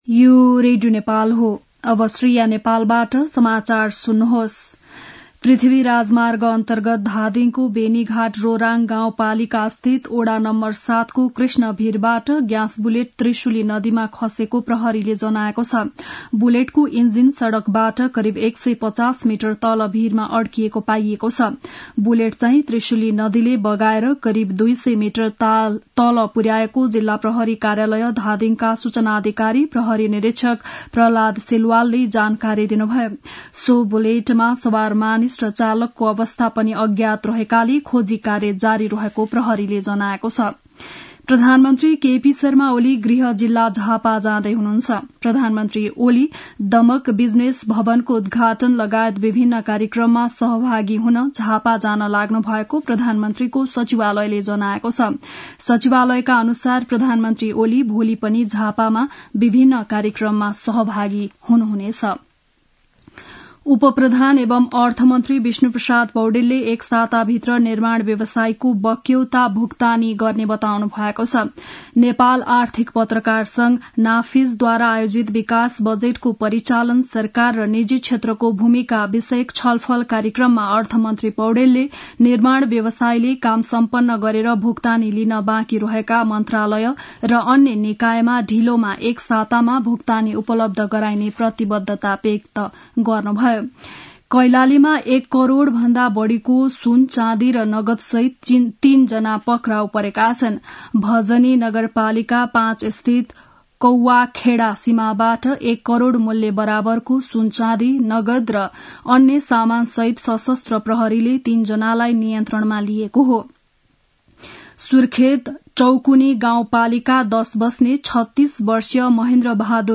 बिहान ११ बजेको नेपाली समाचार : २४ मंसिर , २०८१
11-am-nepali-news-1-6.mp3